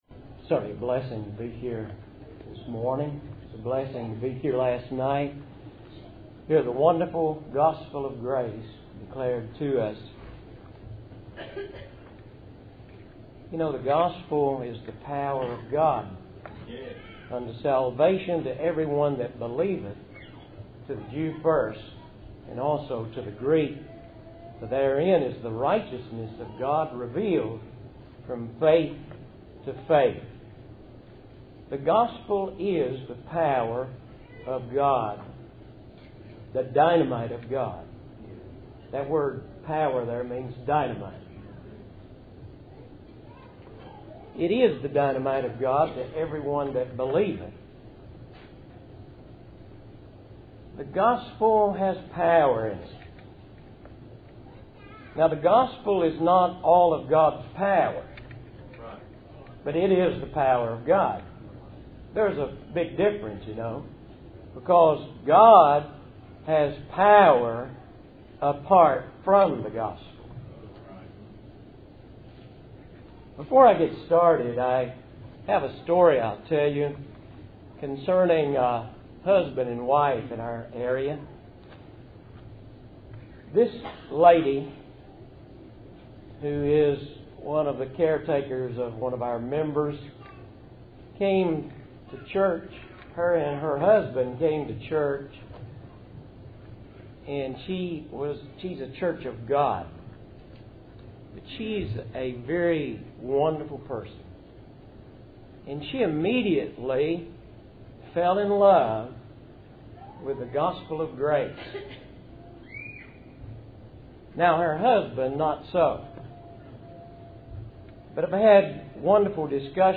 Service Type: Ebenezer Fellowship Meeting